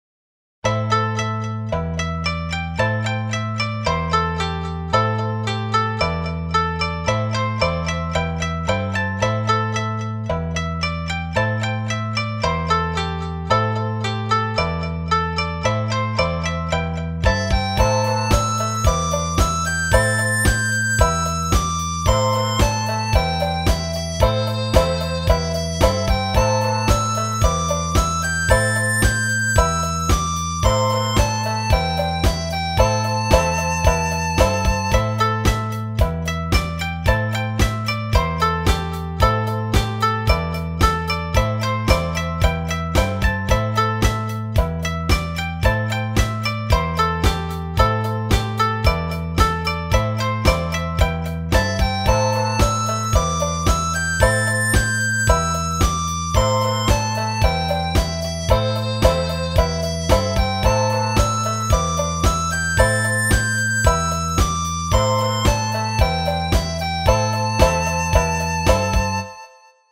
mp3版 / midi版   和風でござる。